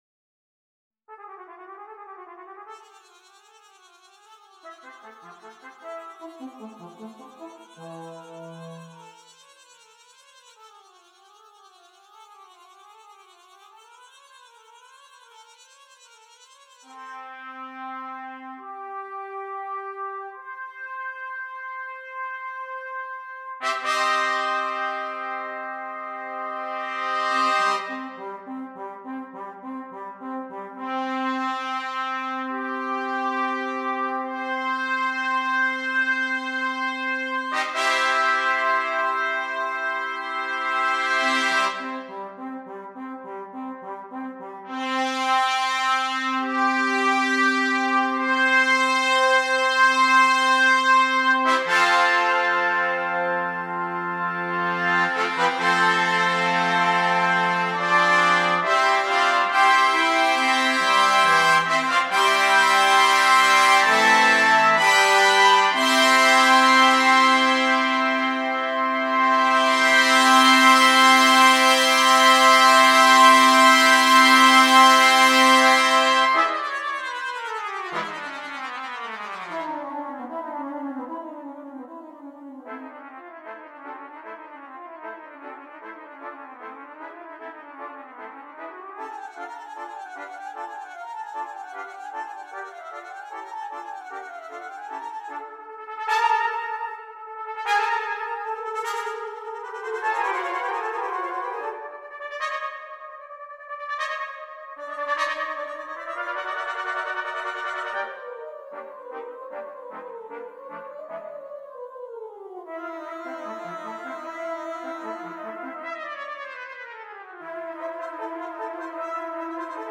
Brass
12 Trumpets